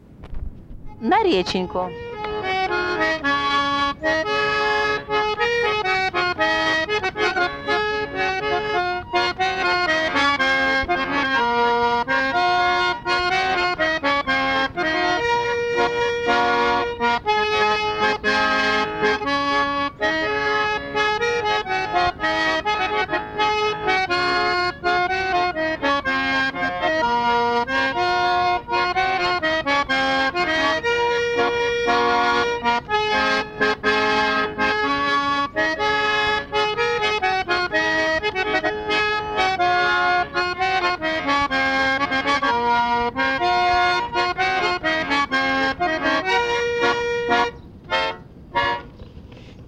ЖанрІнструментальна музика
Місце записус. Шийківка, Борівський район, Харківська обл., Україна, Слобожанщина
гармонь